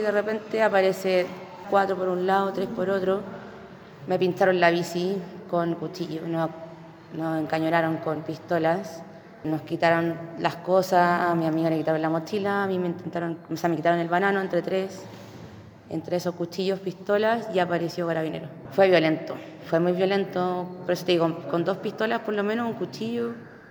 Una de las víctimas relató cómo actuaban los individuos.